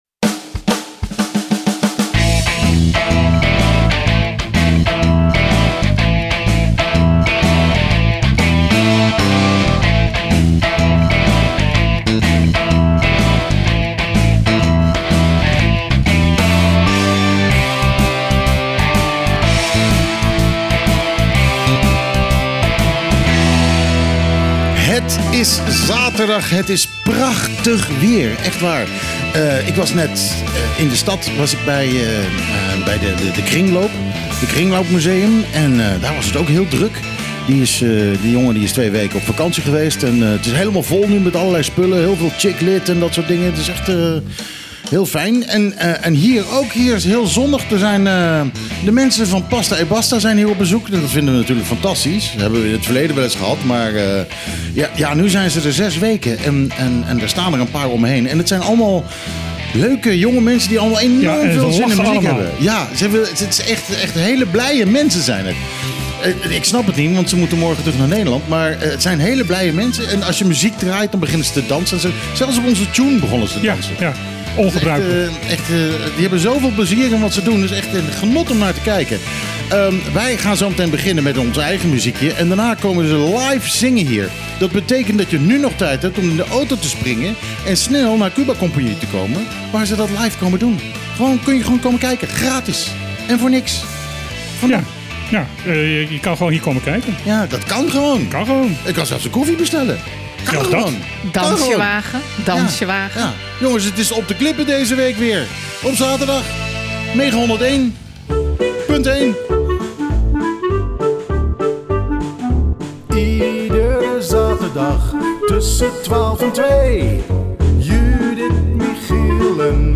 De (bijna) integrale opname van de uitzending van het radioprogramma Op de Klippen. Uitgezonden op 12 juli 2025 vanaf het terras van Cuba Compagnie , Bonaire door het radiostation Mega Hit Fm (101.1 Mhz) Gasten